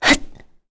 Tanya-Vox_Attack2.wav